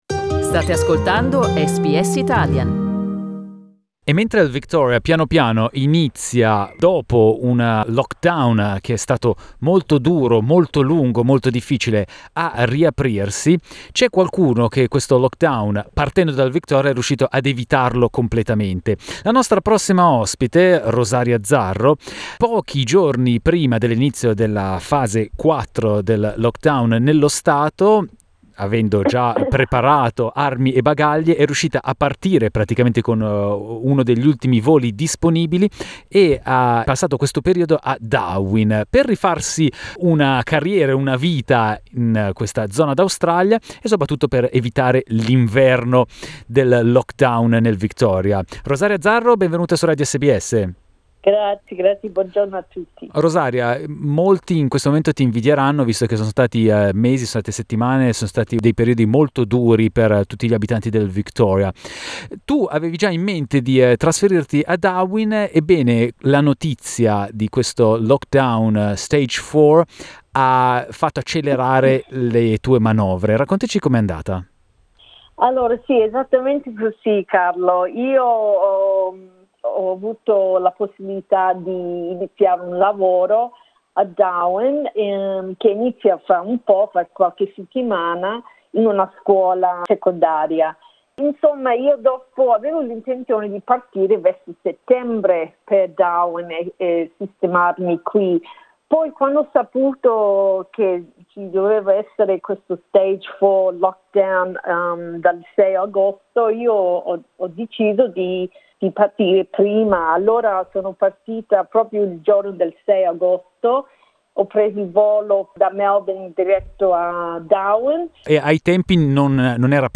Listen to her interview in Italian.